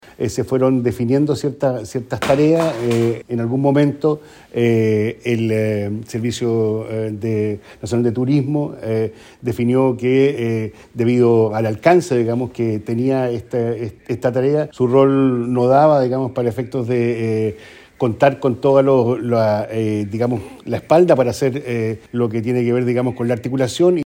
Con delegaciones de más de 50 países se inauguró en el Teatro Municipal de Temuco, región de La Araucanía, la undécima conferencia internacional de Geoparques de la Unesco, que se realiza por primera vez en Sudamérica.
El delegado presidencial de La Araucanía, Eduardo Abdala, dijo que Chile postuló para ser sede de este evento hace tres años, agradeciendo al Gobierno Regional por otorgar el financiamiento, ya que el Sernatur no tenía recursos.